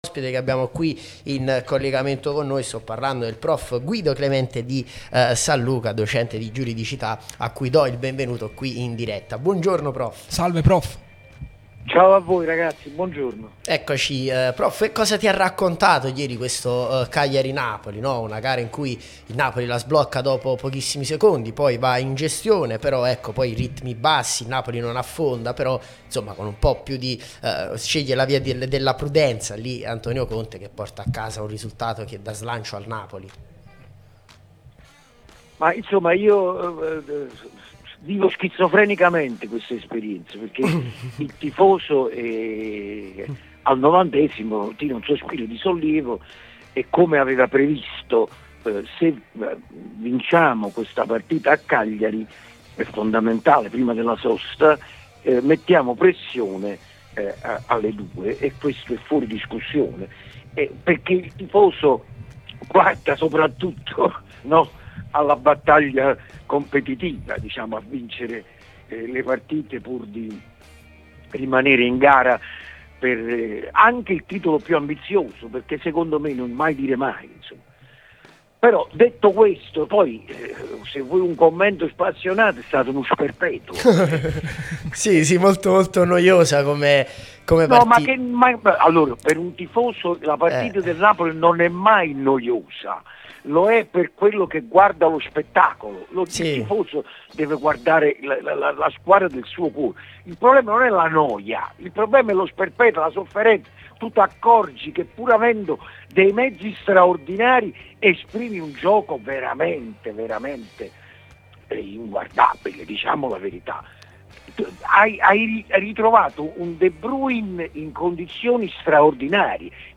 'Sabato Sport', trasmissione